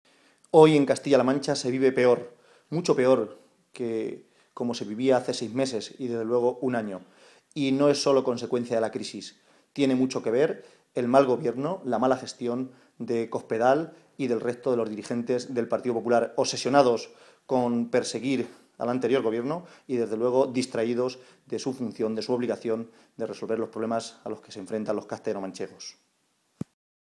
José Manuel Caballero, secretario de Organización del PSOE de Castilla-La Mancha
Cortes de audio de la rueda de prensa